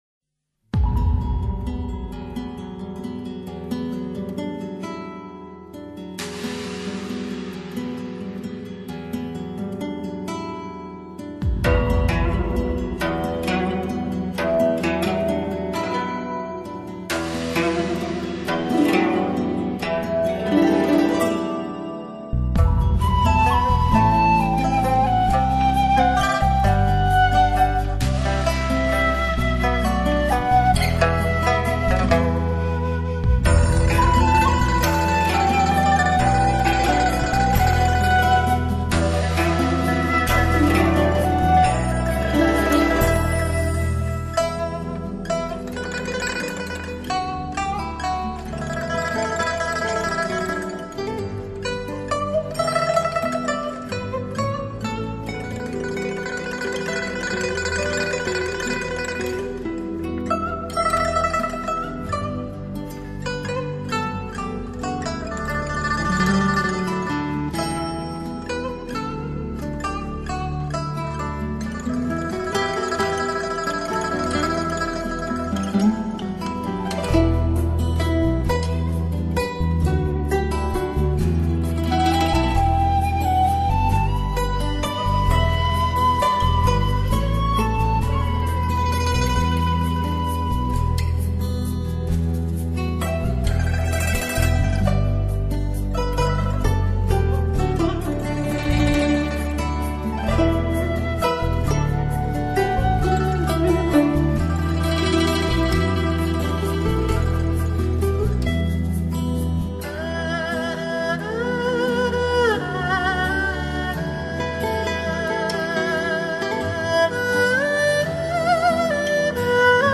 以最东方的美色走出最世界的靓声，
超卓的音色，
准确的定位，
唯美的演绎，